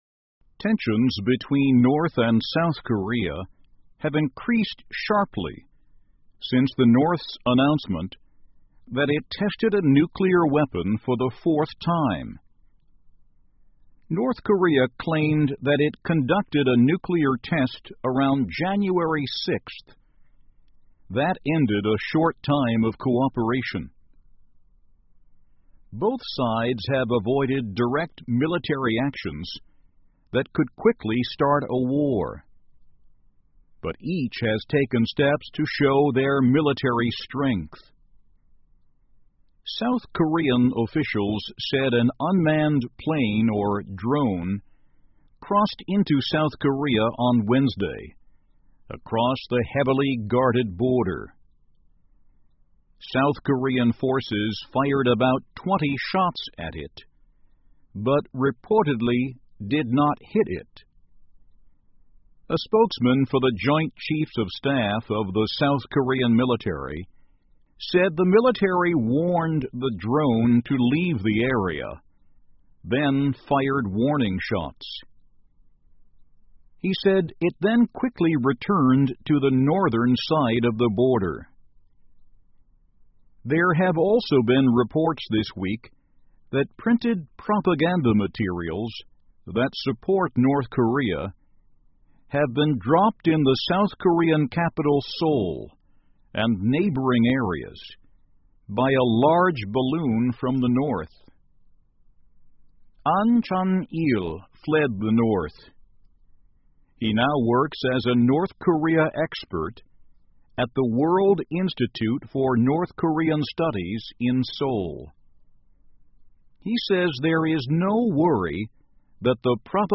VOA慢速英语2016--朝鲜与韩国紧张局势加剧 听力文件下载—在线英语听力室